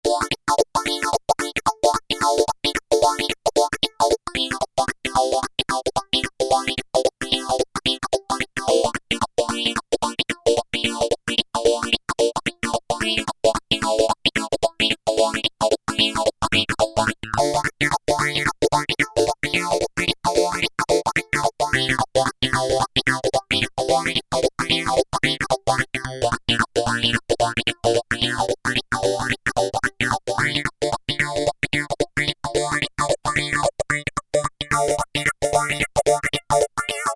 F08 - Cutting Arpg Excellent emulation of a wah-wah guitar being strummed!